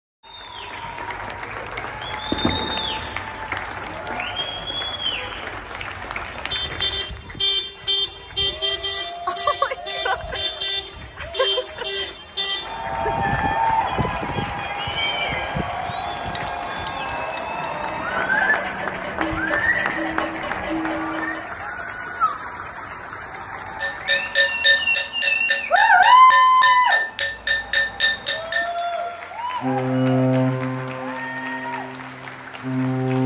It is the sound of the street in Aberdeen where I live recorded on April 2nd2020.
Different sounds are present on the sound file.  Clapping is coming from the doorways from just about each of the bungalows on one side of the street or people leaning out their tenement windows on the other. Car horns are also present.  The eerie other worldly sounds that dominate the file are fog horns from the ships in Aberdeen harbour that supply oil rigs in the North Sea.
It was the volume of what was happening that evening that prompted me to lean out my window with my Ipad and make the recording.  The fog horns are loud in themselves, intended to work over the vastness of the North Sea, but it was the clapping that cut through the most.
clap-for-carers-millions-applaud-nhs-staff-on-coronavirus-frontlines_g8ON4m0s.mp3